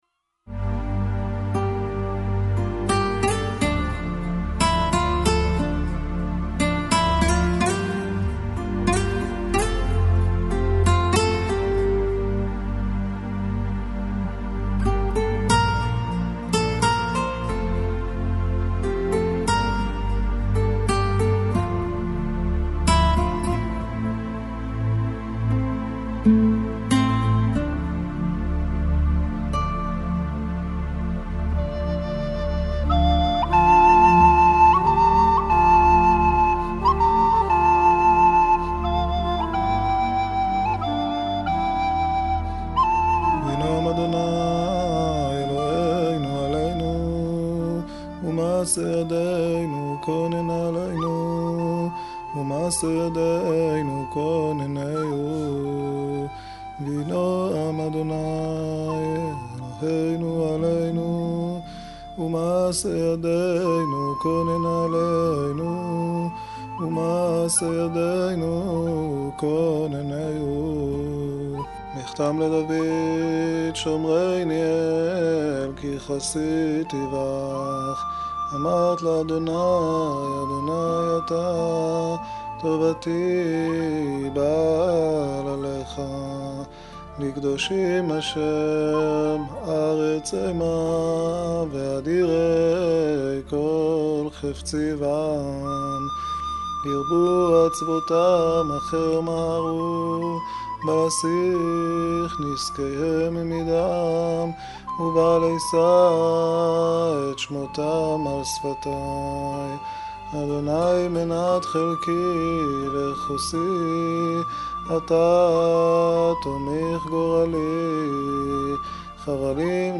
תפילות להאזנה